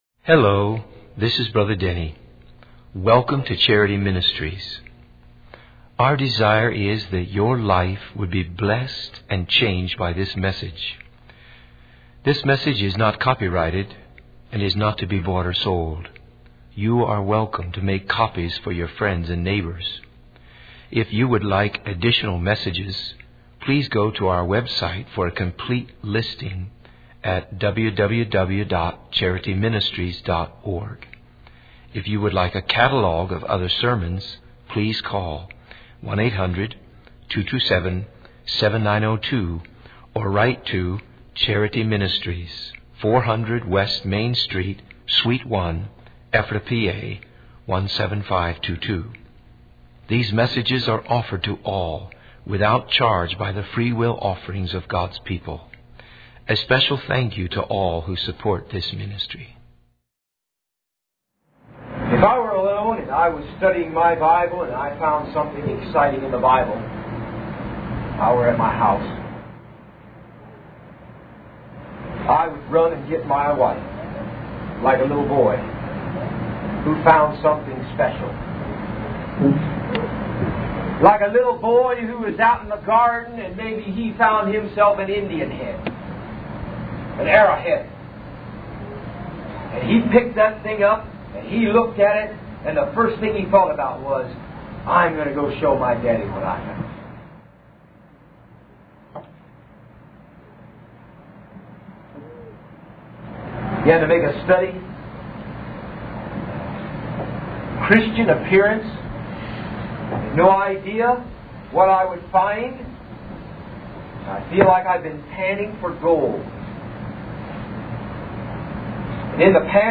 In this sermon, the preacher describes a situation where he had a planned sermon but felt a burden on his heart as he saw young people gathering for the meeting. He emphasizes the importance of hearing and obeying the word of God, as it brings joy to the heart.